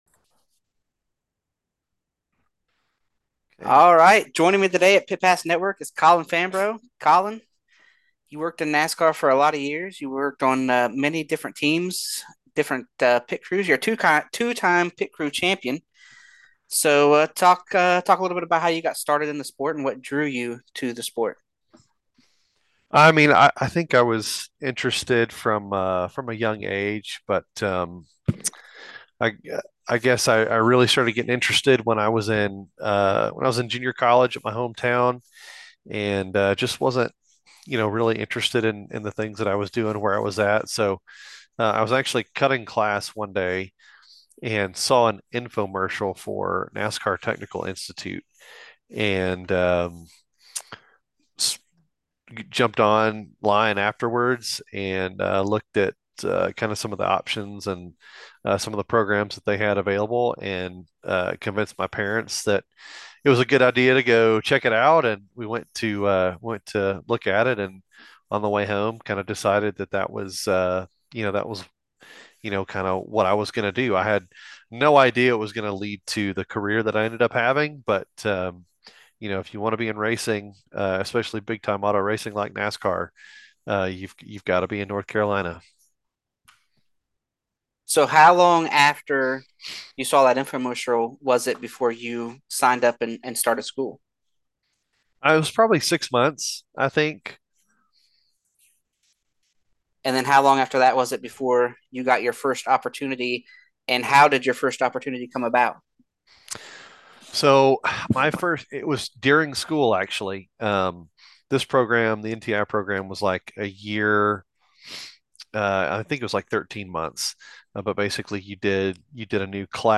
Pit Pass Network Interview